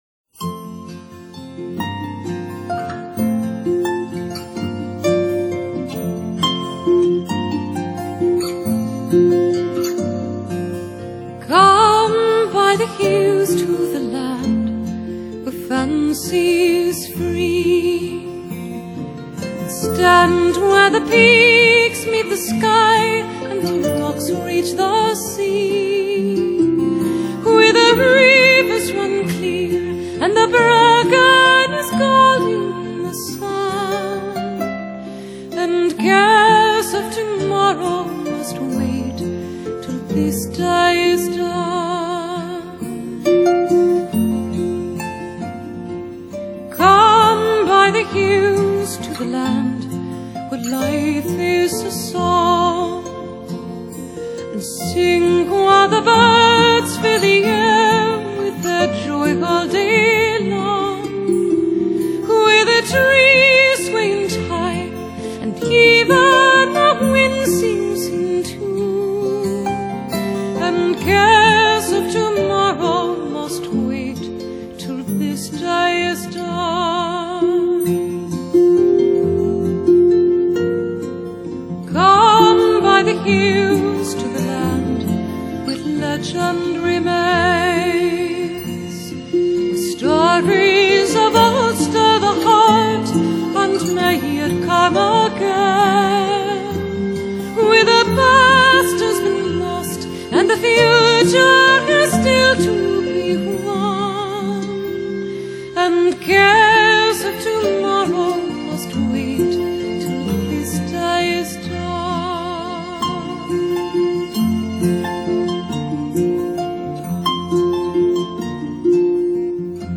【凱爾特】